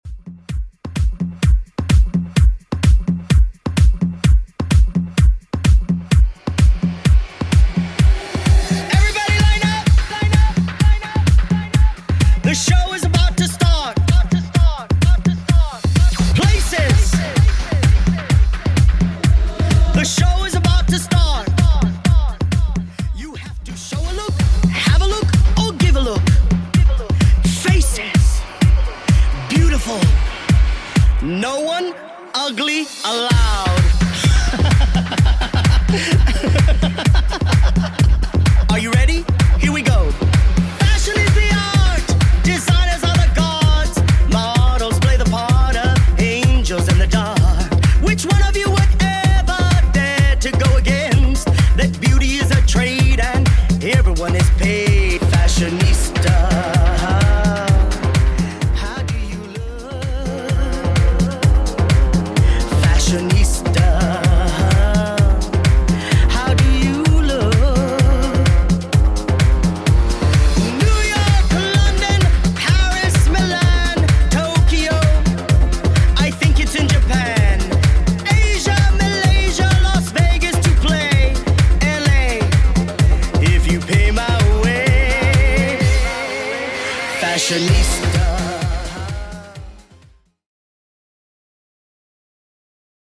Genre: 90's
Clean BPM: 96 Time